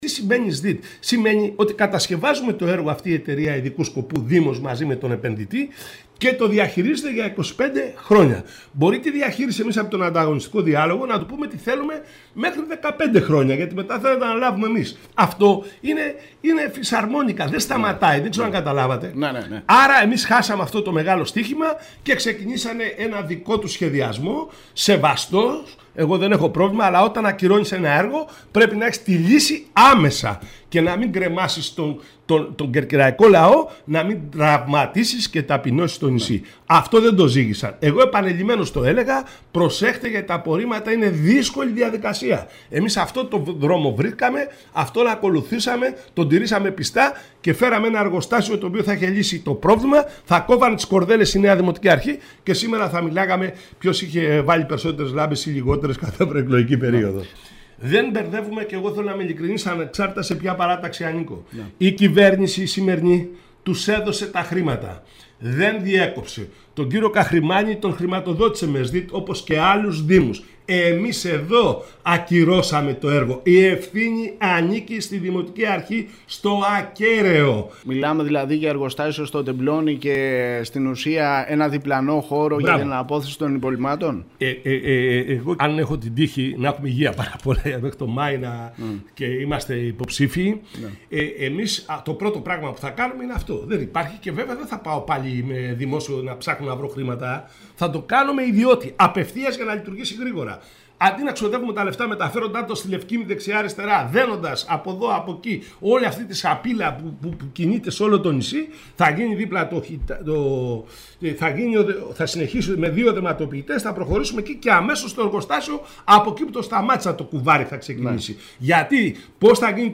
Οι χρηματοδοτήσεις έχουν διατεθεί από το κεντρικό κράτος, η λύση στο θέμα των απορριμμάτων της Κέρκυρας πρέπει να δοθεί με εργοστάσιο ολοκληρωμένης διαχείρισης μέσω ΣΔΙΤ. Αυτό τόνισε μιλώντας στην ΕΡΤ Κέρκυρας ο πρώην Δήμαρχος και επικεφαλής της δημοτικής παράταξης «Κέρκυρα να Ζεις» Γιάννης Τρεπεκλής. Ο κ. Τρεπεκλής επισήμανε ότι θα πρέπει να ολοκληρωθεί ο σχεδιασμός από το σημείο που έμεινε με ανάπτυξη εργοστασίου ολοκληρωμένης διαχείρισης στο Τεμπλόνι και εναπόθεση των υπολειμμάτων σε διπλανό χώρο που μένει να διαμορφωθεί.